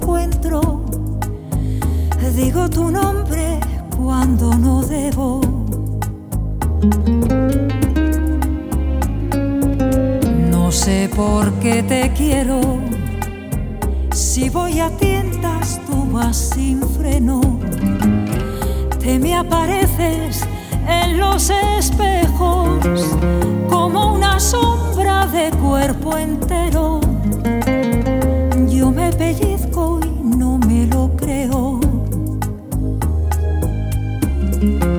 Скачать припев
Pop Latino